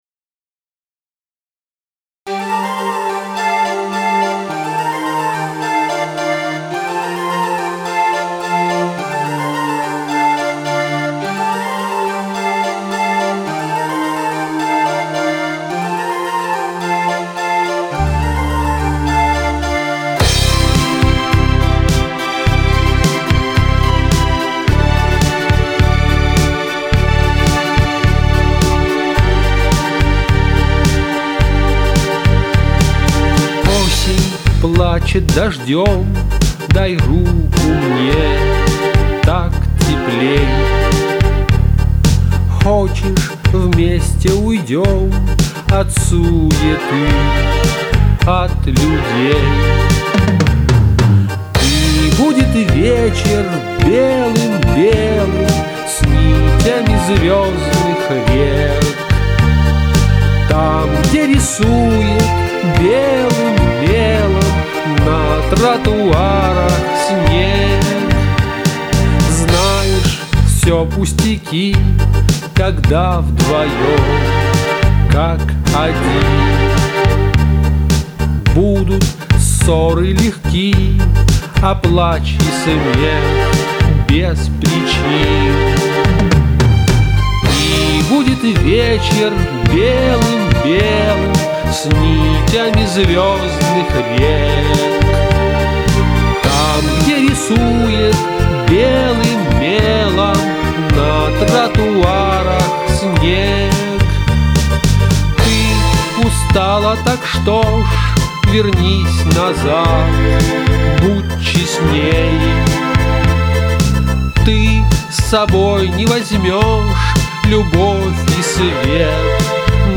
Повествовательные, по-мужски сдержанные раздумья